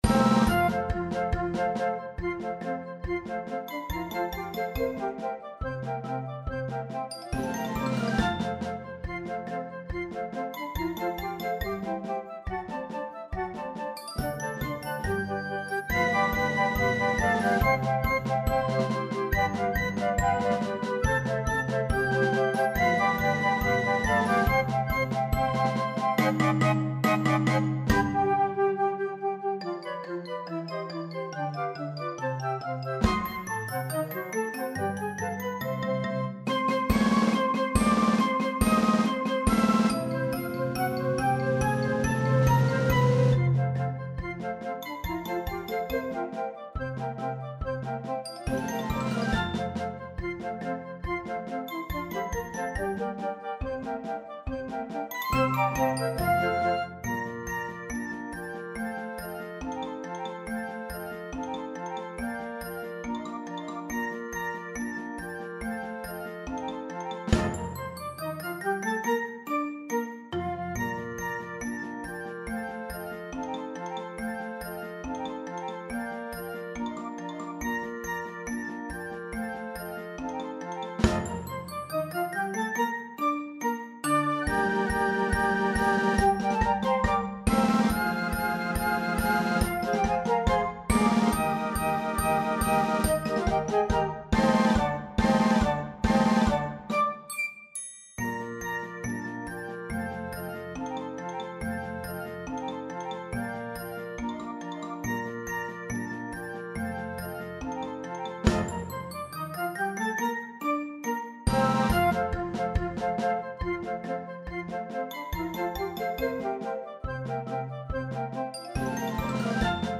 Demo Flötenorchester